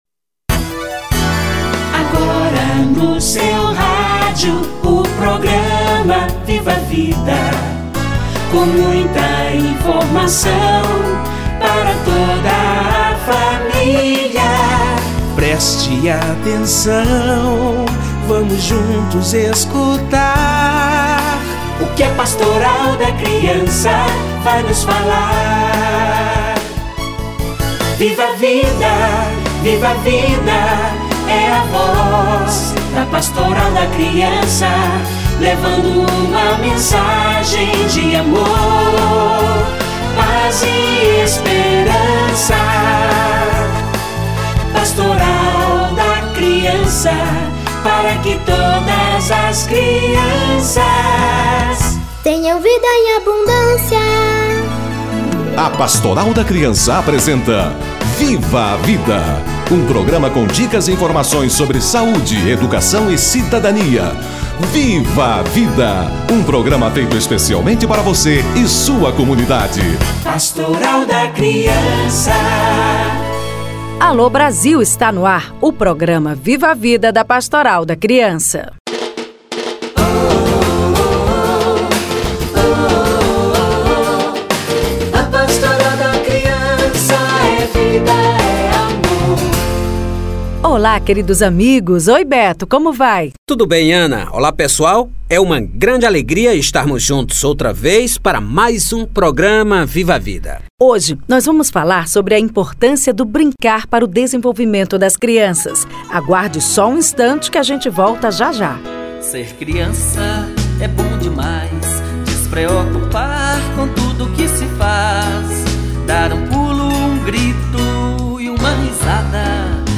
O brincar - Entrevista